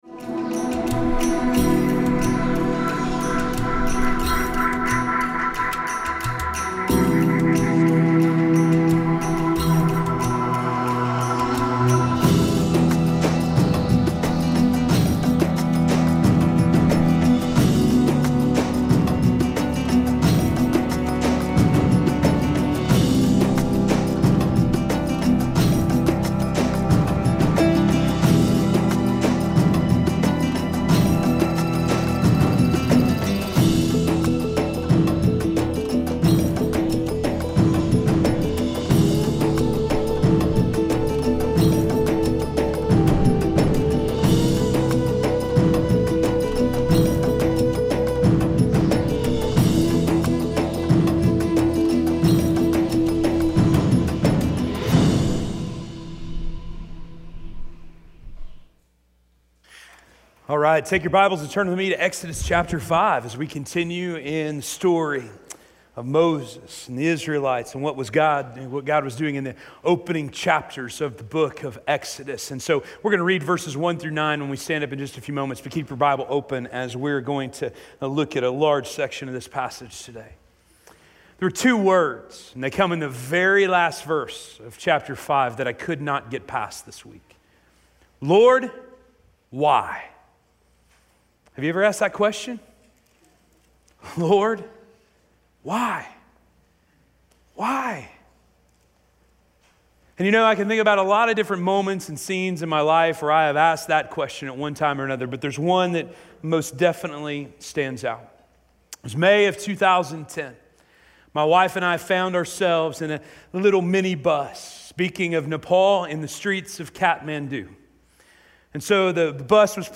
The Impossibility of Evacuation: Desperate - Sermon - Station Hill